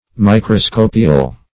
Microscopial \Mi`cro*sco"pi*al\, a.
microscopial.mp3